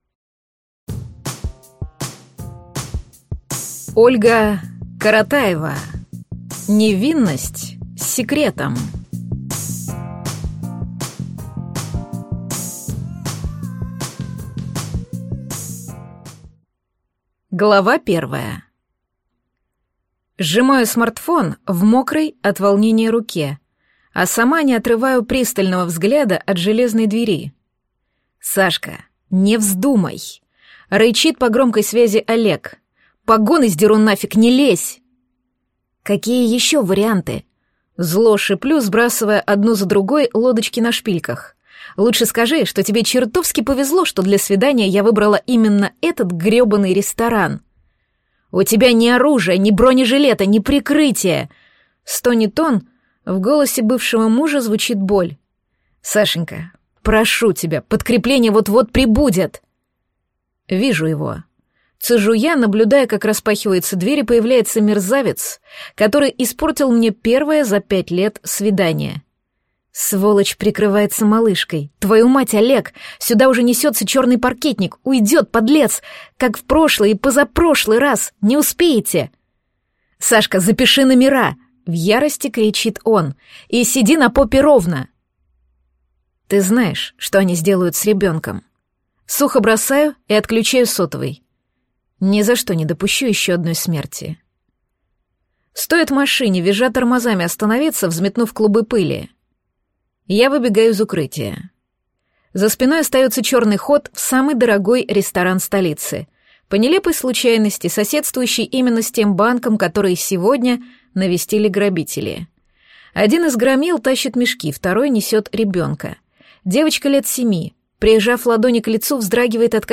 Аудиокнига Невинность с секретом | Библиотека аудиокниг